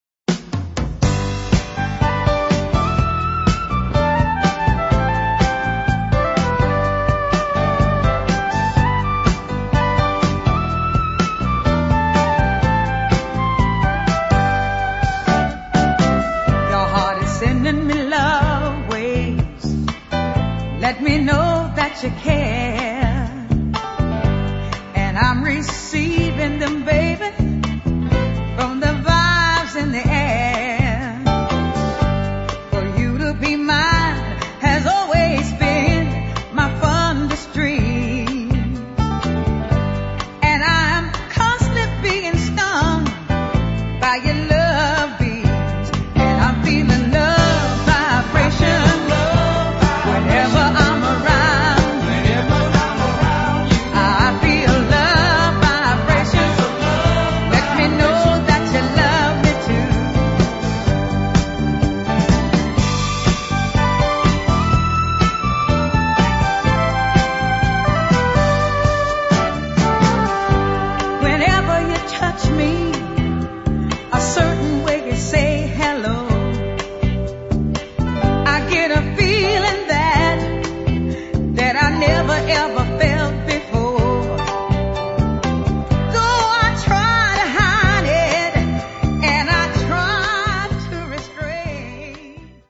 neat 70's dancer